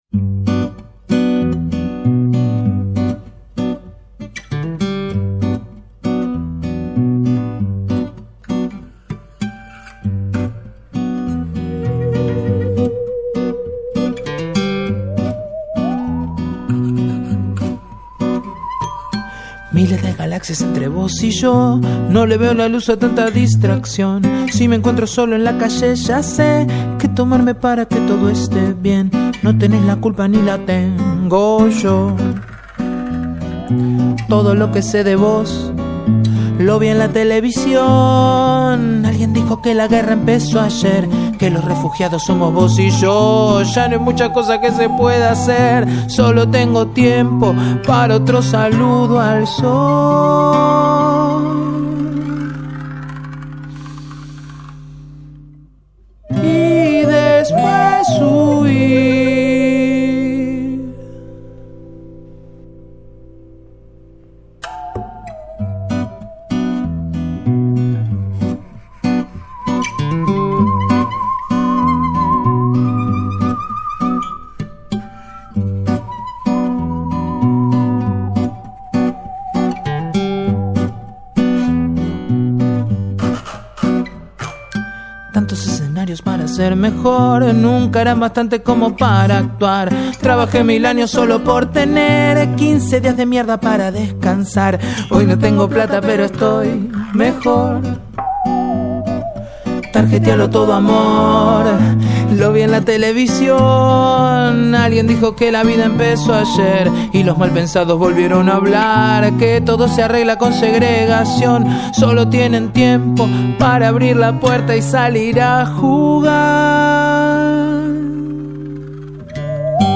Guitarras, Voz, Metalofón y Teclado.
Saxo Baritono
Saxo alto y tenor
Trompeta